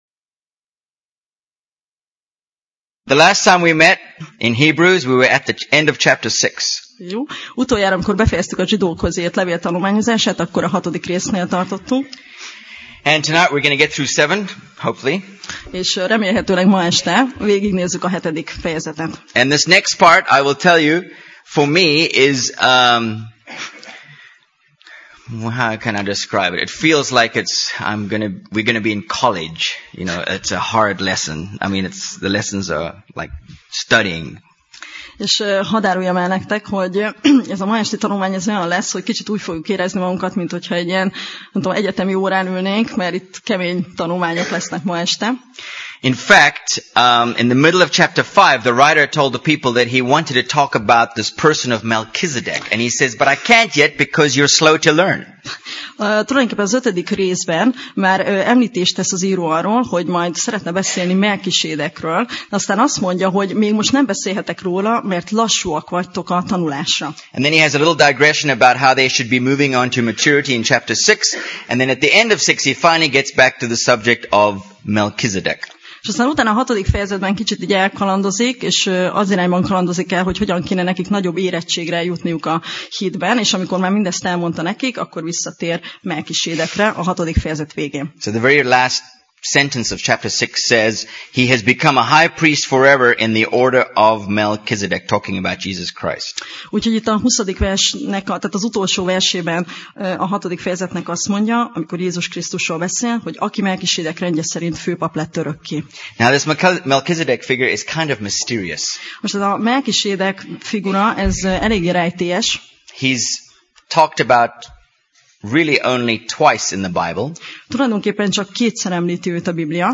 Alkalom: Szerda Este